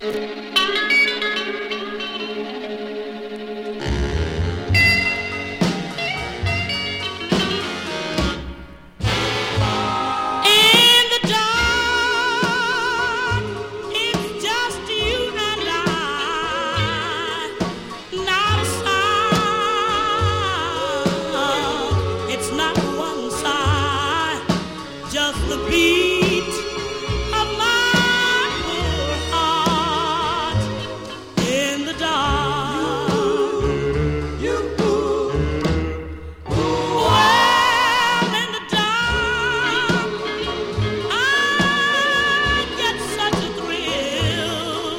Rhythm & Blues, Rock 'N' Roll　UK　12inchレコード　33rpm　Stereo